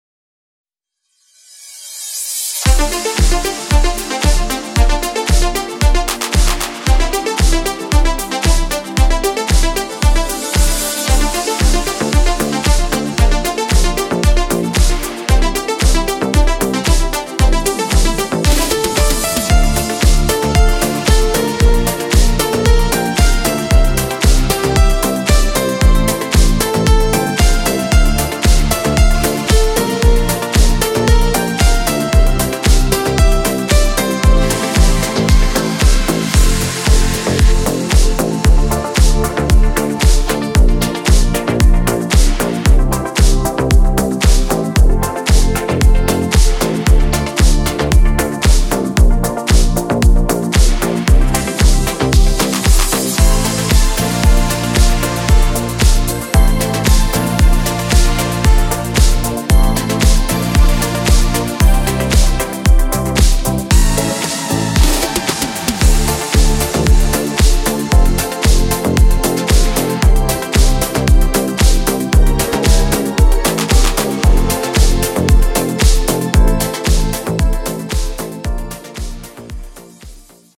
Nastrojowy hit z lat 80-tych
w nowej tanecznej odsłonie, dostępny jako instrumental